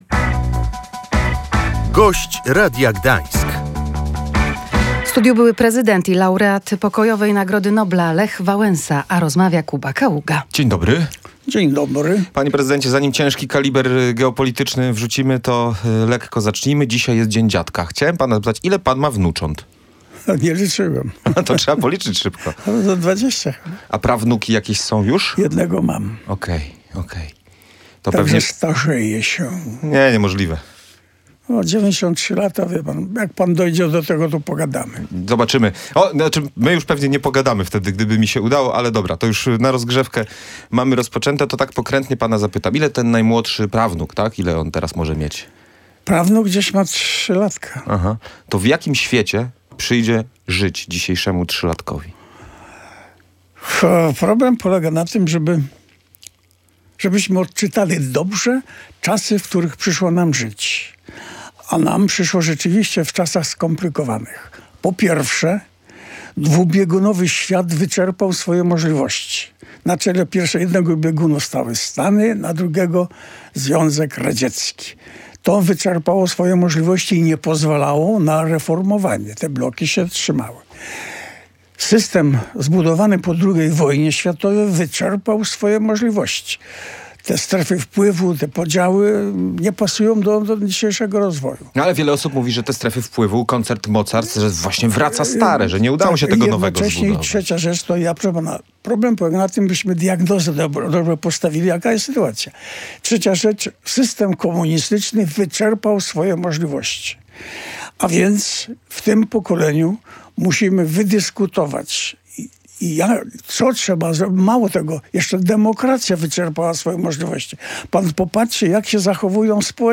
Czas zbudować nową demokrację – mówił w Radiu Gdańsk Lech Wałęsa.